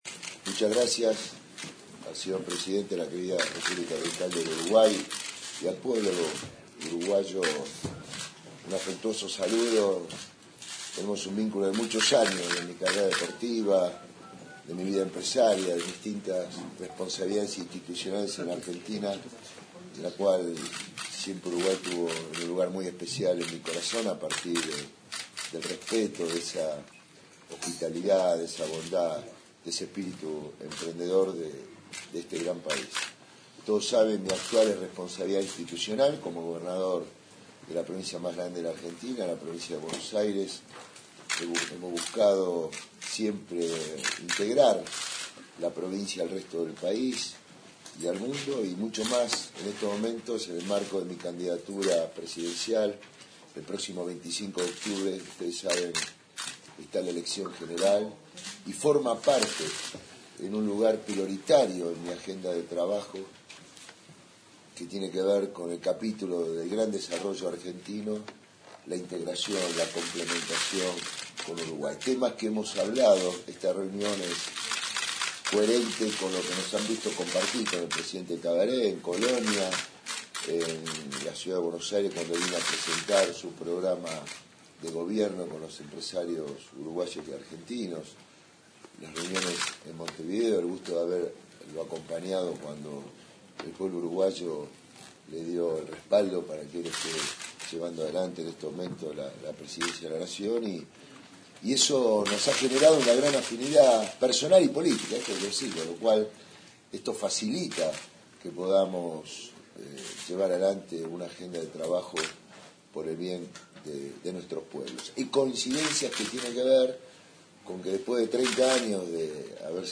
Habla Scioli